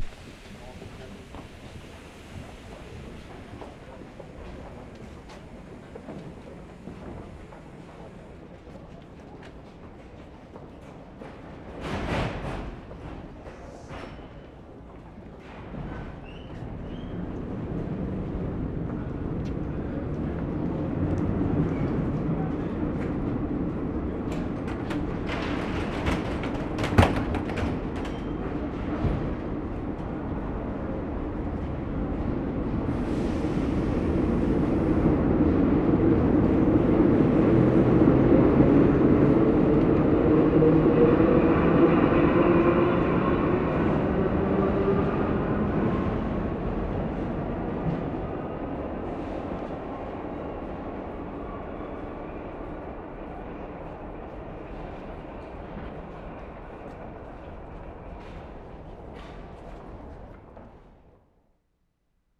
Paris, France April 8/75
DOORS CLOSING AT CONCORDE, from platform.